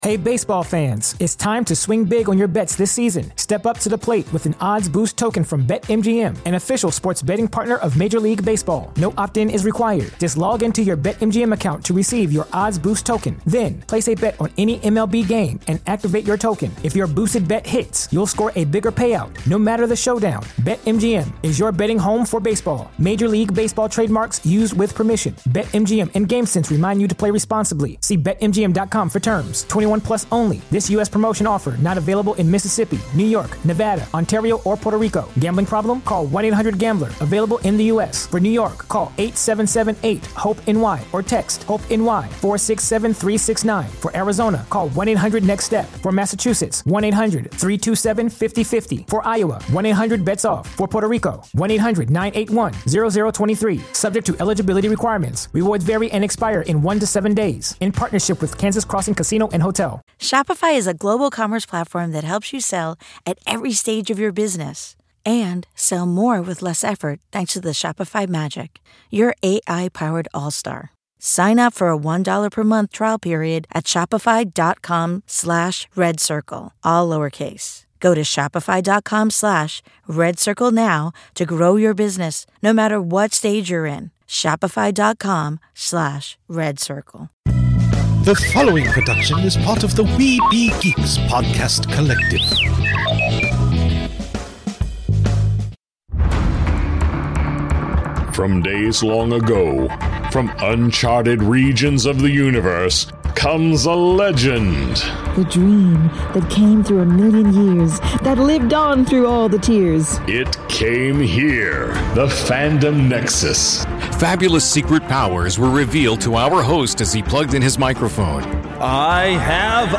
You may have noticed some ads as part of the show this week.